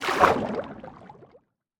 Minecraft Version Minecraft Version latest Latest Release | Latest Snapshot latest / assets / minecraft / sounds / ambient / underwater / enter3.ogg Compare With Compare With Latest Release | Latest Snapshot